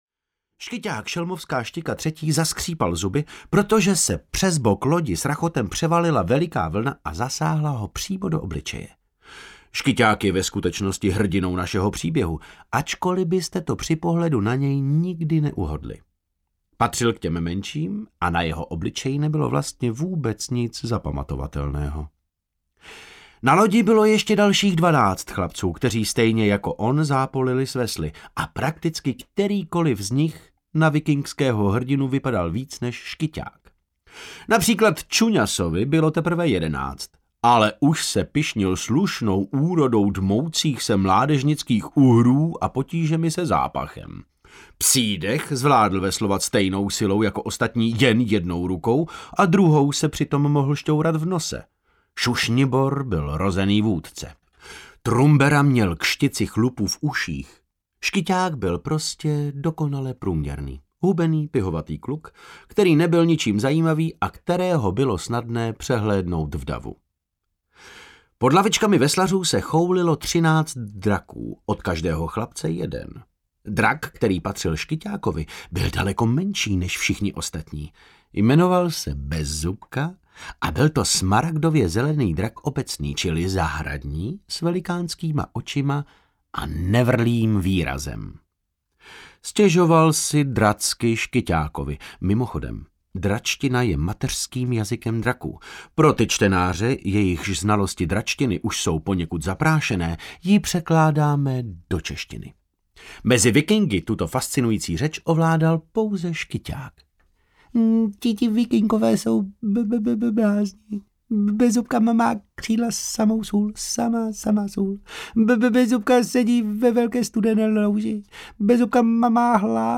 Audiokniha
Čte: David Novotný